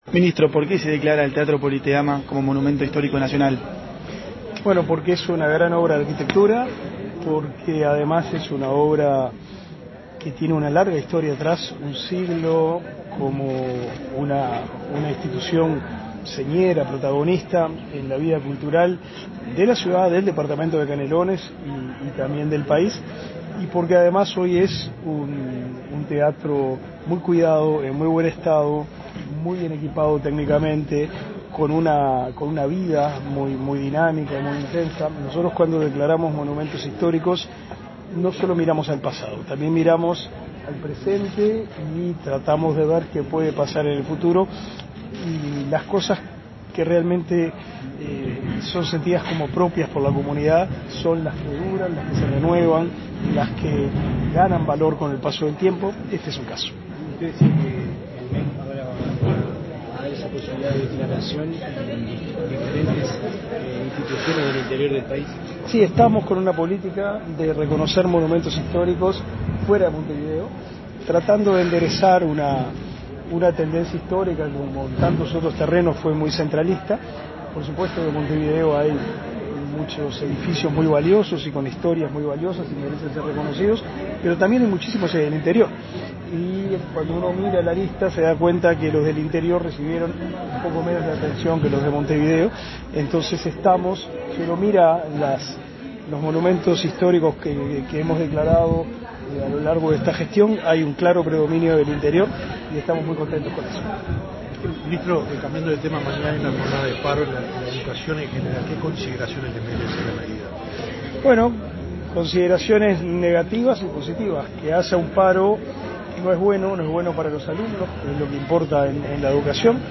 Declaraciones a la prensa del ministro de Educación y Cultura, Pablo da SIlveira
Tras el evento, el ministro Da Silveira efectuó declaraciones a la prensa.